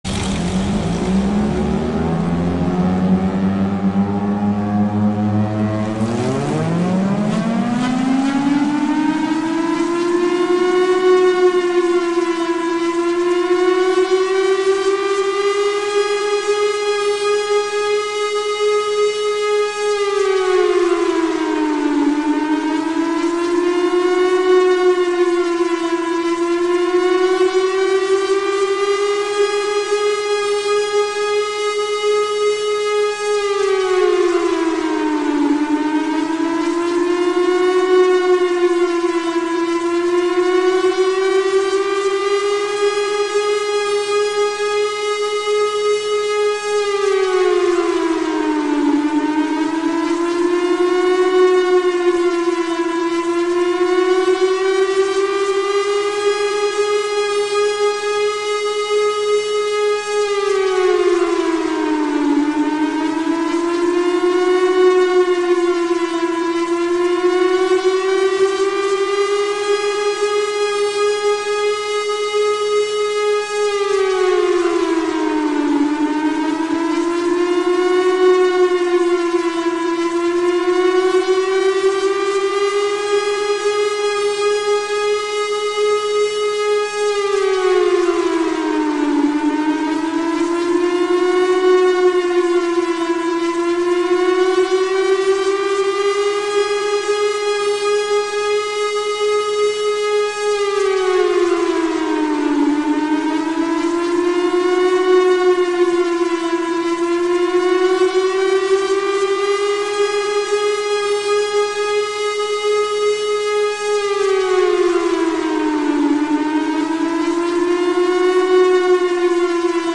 ChryslerAirRaidSiren.mp3